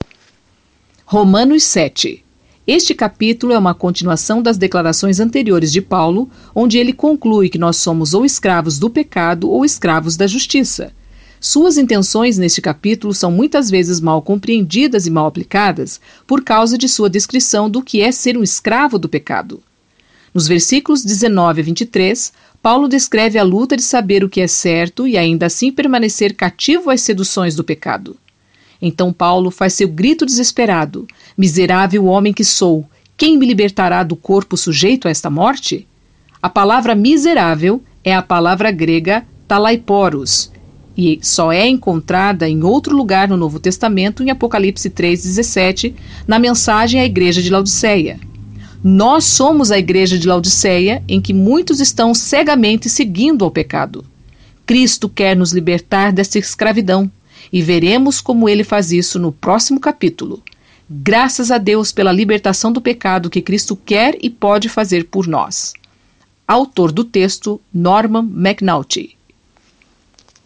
Comentário em áudio